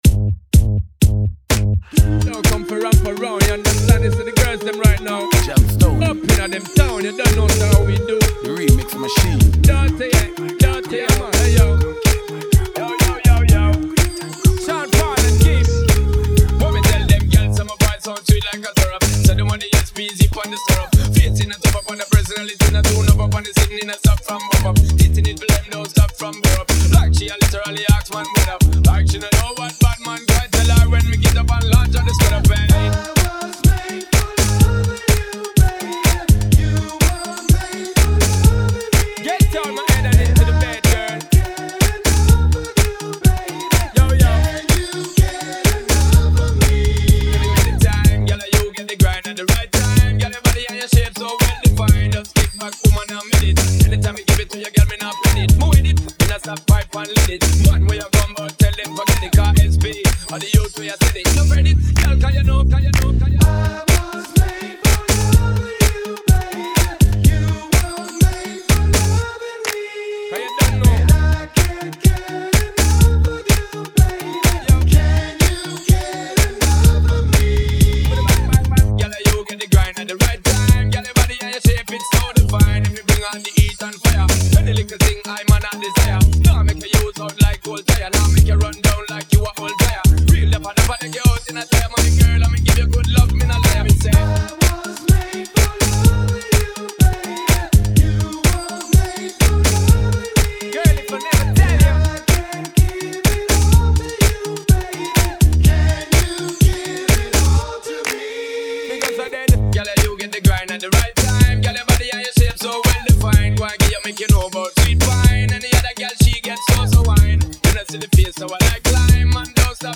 Vocal tracks
riddim